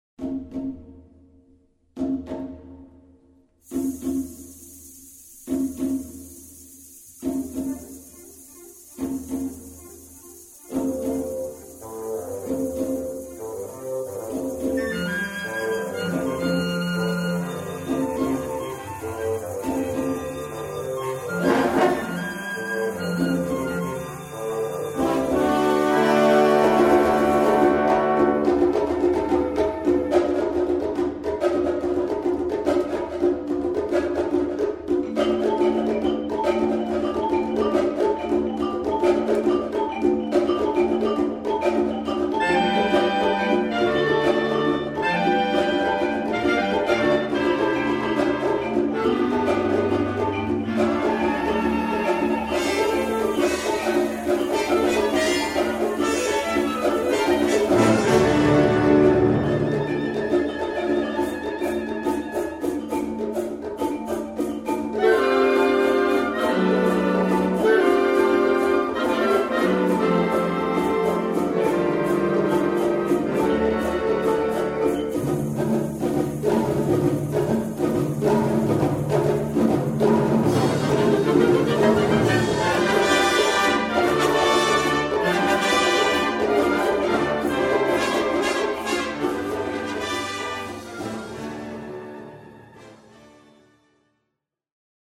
Répertoire pour Orchestre